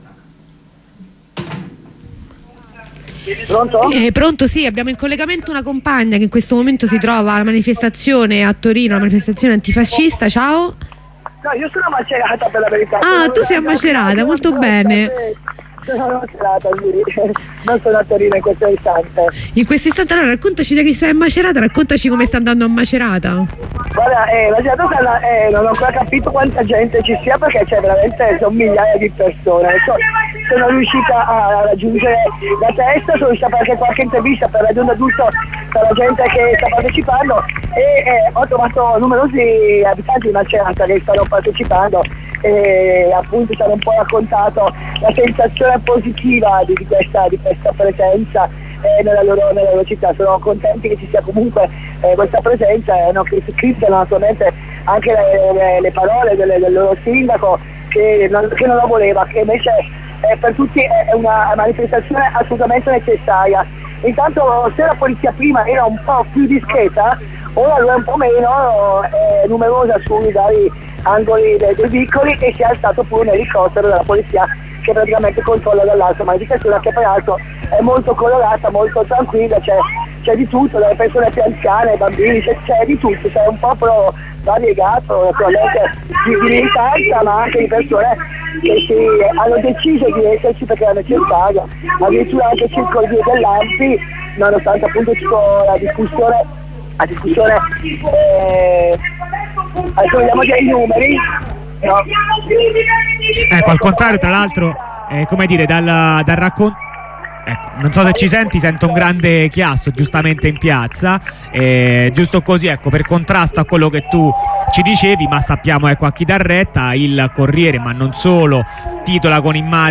Macerata: tutti gli interventi dal corteo
voci dalla piazza